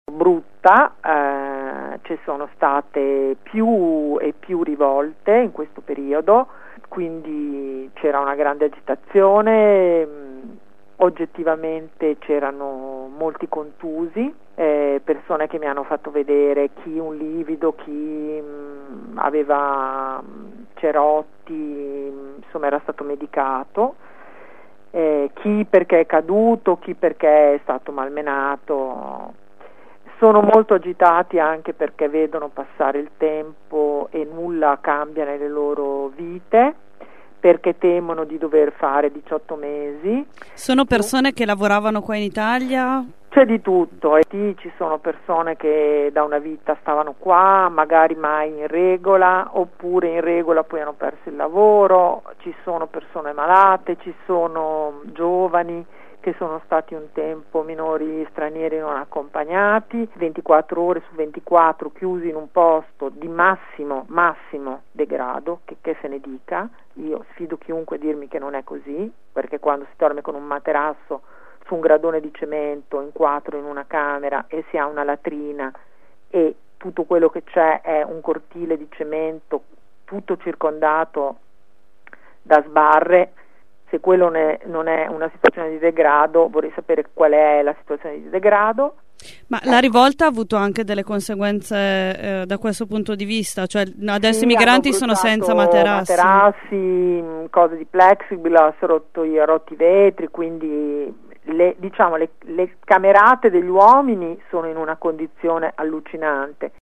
“Da una parte si firmano protocolli antimafia, dall’altra si emettono bandi che non possono essere vinti da aziende in regola, perché è matematicamente impossibile”, così la deputata del Pd Sandra Zampa critica ai nostri microfoni il comportamento della Prefettura, all’uscita dal centro di via Mattei per la campagna “LasciateCIE entrare“.